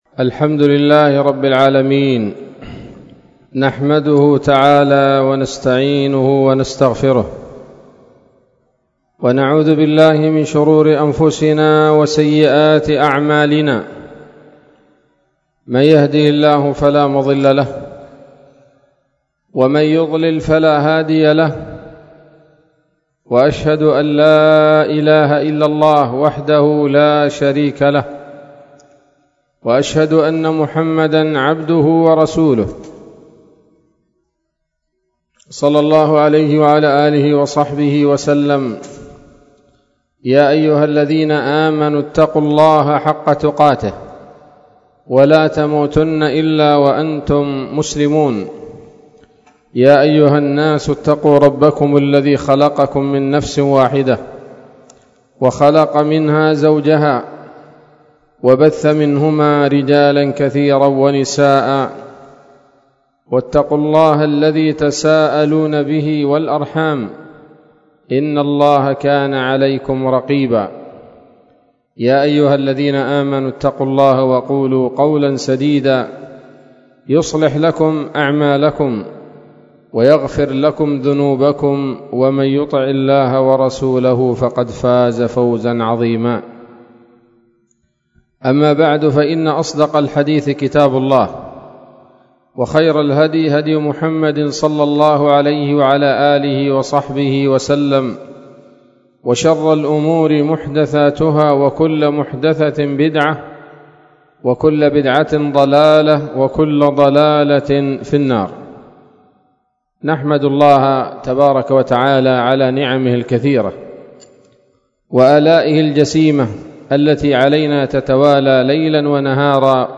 كلمة قيمة بعنوان: (( ‌شرح الرحلة إلى بلاد شبوة وحضرموت والمهرة )) ليلة الخميس 21 شوال 1444هـ، بدار الحديث السلفية بصلاح الدين